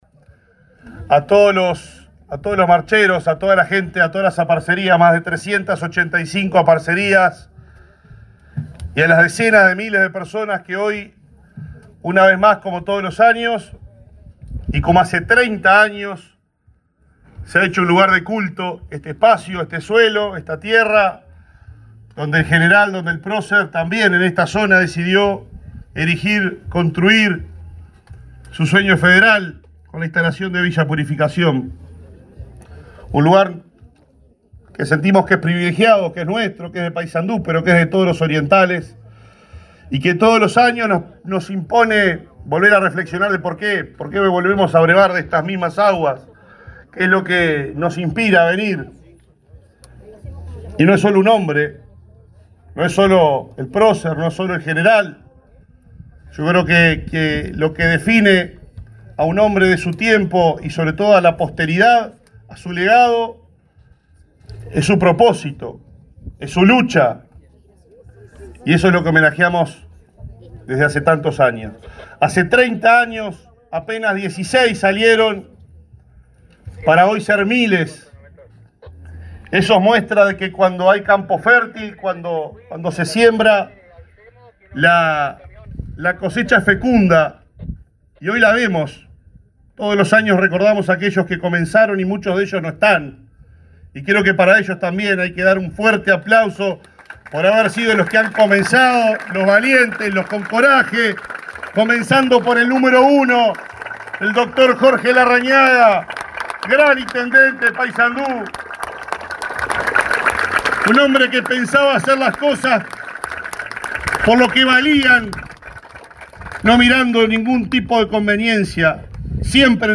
Palabras del intendente de Paysandú, Nicolás Olivera
Este domingo 22, el intendente de Paysandú, Nicolás Olivera, participó en el 30.° Encuentro con el Patriarca, en la meseta de Artigas, ubicada en ese